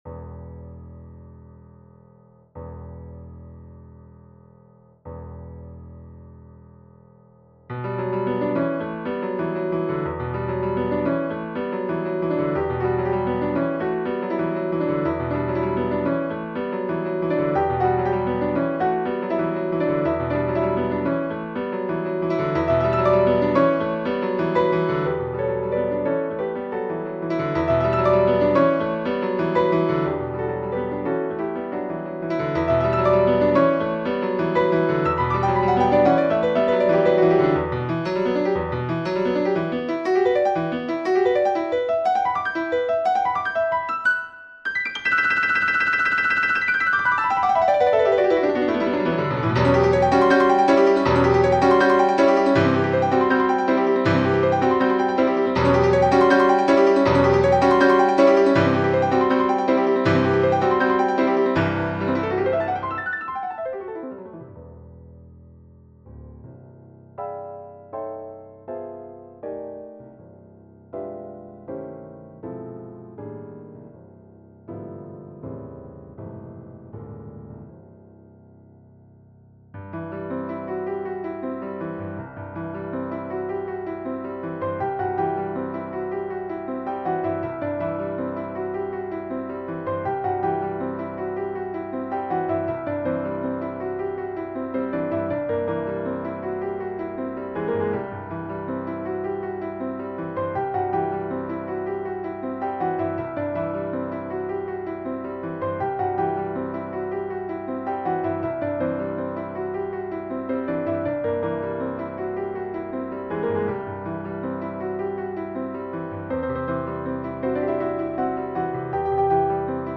For solo Piano: